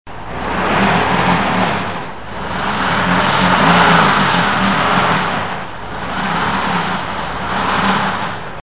Car Stuck On Ice
Cars